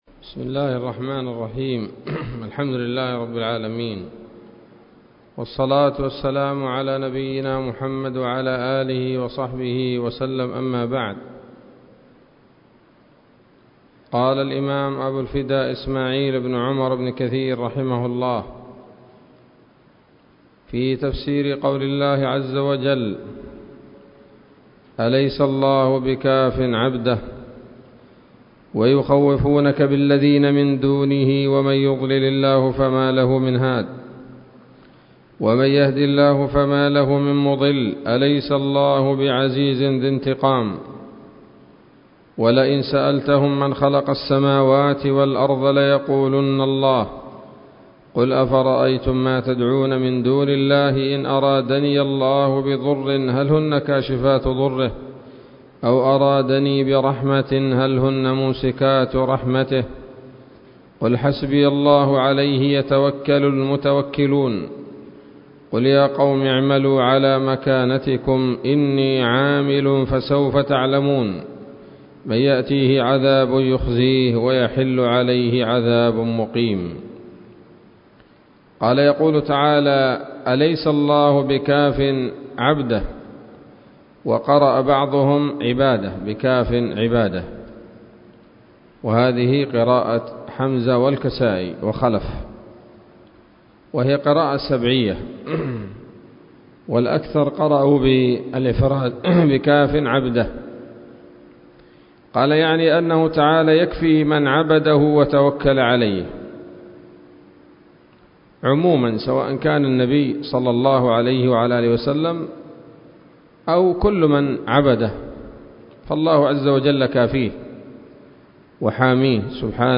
039 سورة الزمر الدروس العلمية تفسير ابن كثير دروس التفسير
الدرس العاشر من سورة الزمر من تفسير ابن كثير رحمه الله تعالى